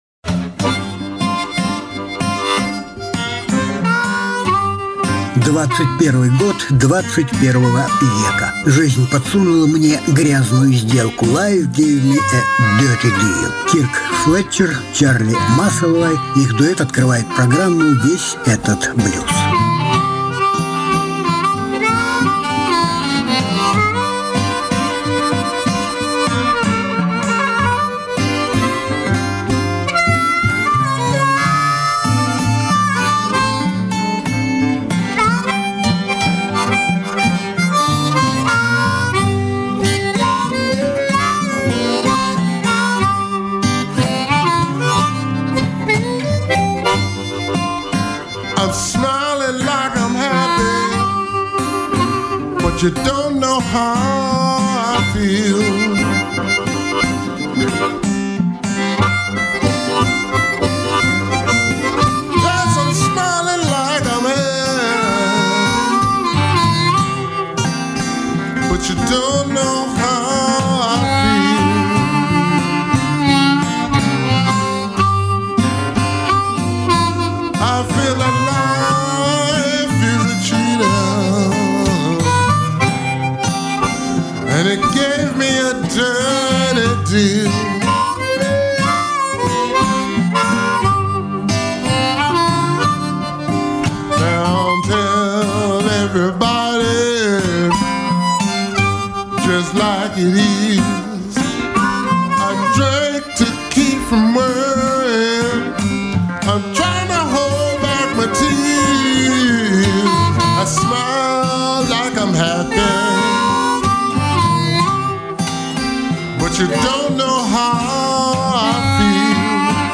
Различные исполнители Жанр: Блюзы и блюзики СОДЕРЖАНИЕ 11.01.2021 Новинки 2020 года.